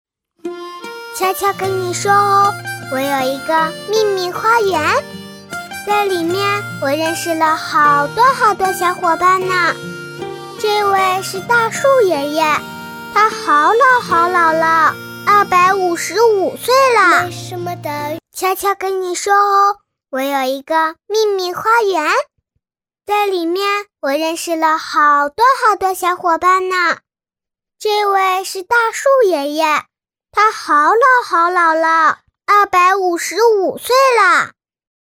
• 房地产广告配音
女B32-真童 地产广告
女B32-真童 地产广告.mp3